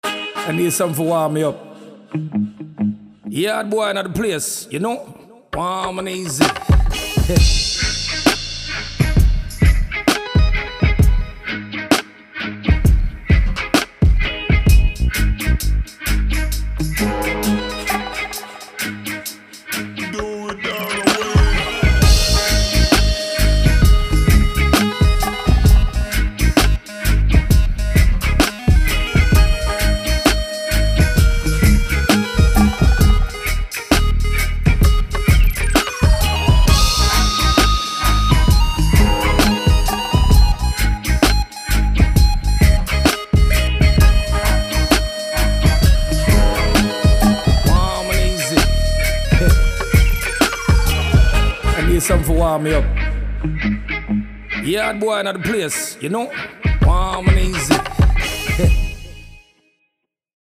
SIZZLING DANCEHALL HIP HOP
– Jam with thick bass, vocal flavors, and vibey rhythms
Demo